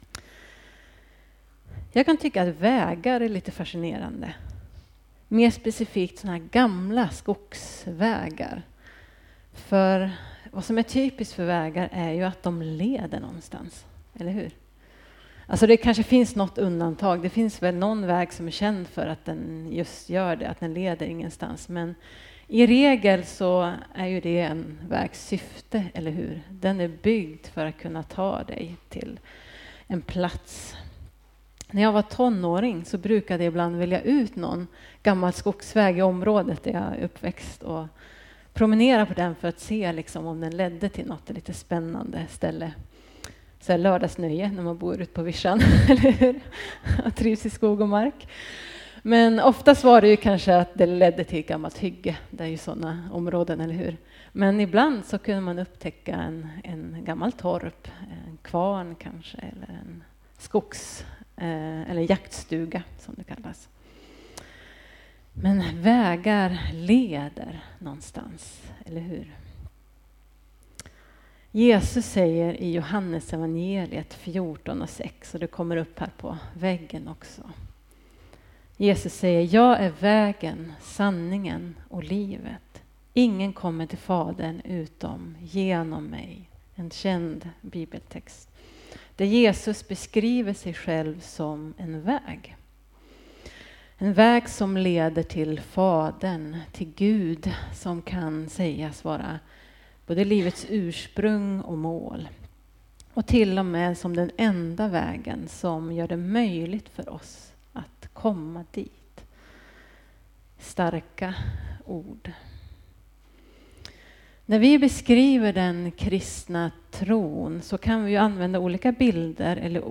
Predikningar Elimkyrkan Gammelstad